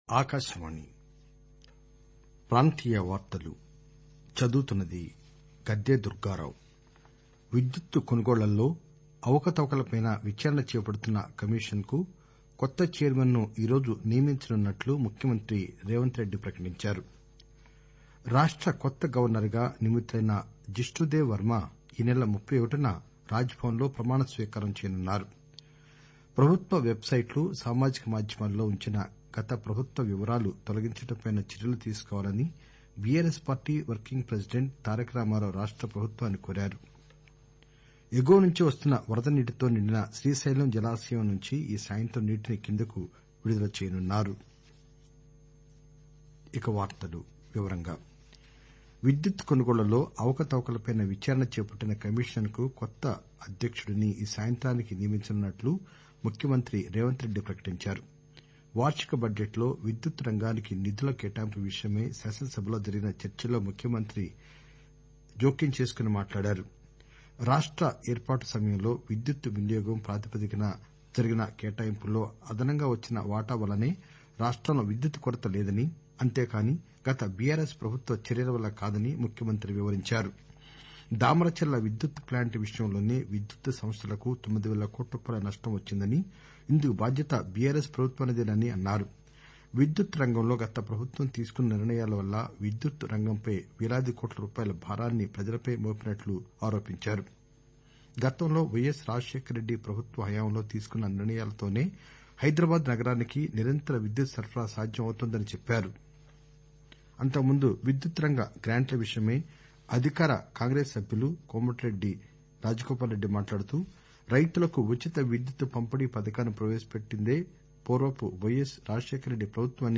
REGIONAL-TELUGU-NEWS-BULLETIN-1310.mp3